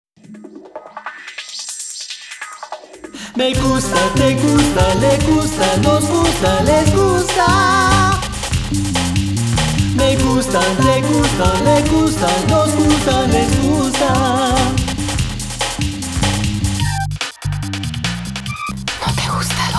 Spanish CD or Album Download